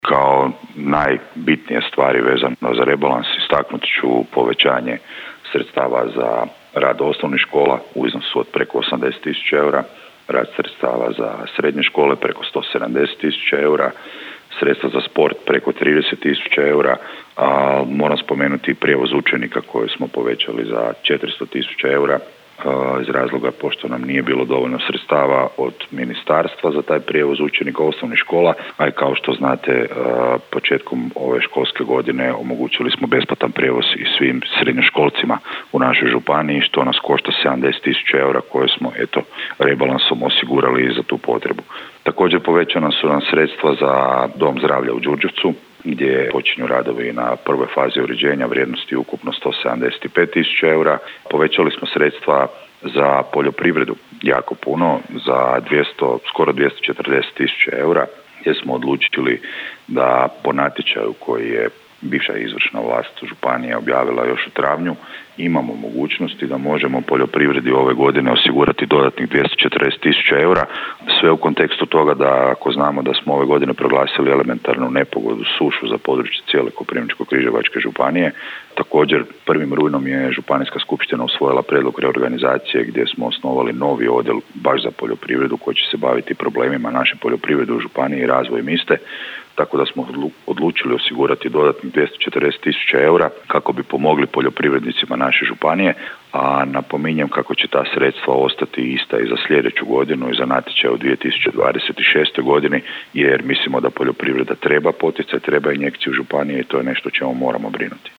Županijski skupštinari okupili su se na trećoj sjednici Županijske skupštine Koprivničko-križevačke županije u aktualnom sazivu.
– rekao je župan Tomislav Golubić.